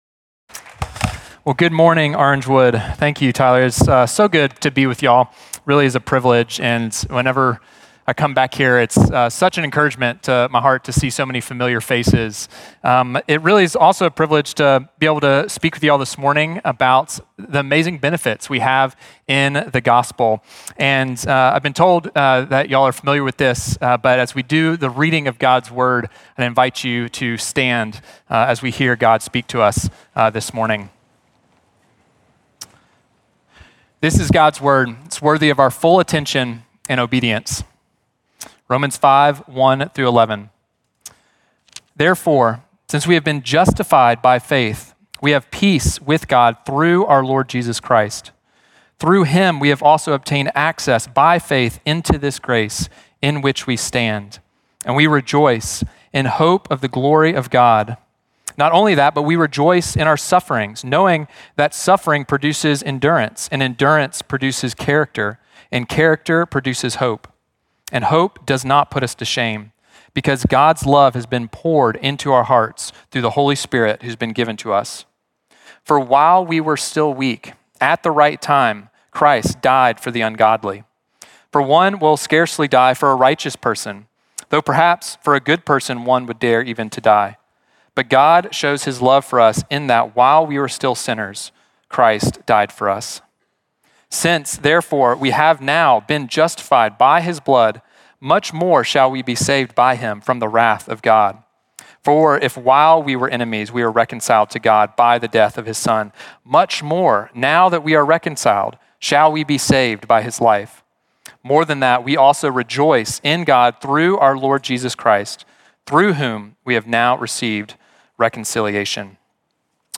Orangewood Church Maitland Orlando Florida